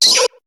Cri de Rondoudou dans Pokémon HOME.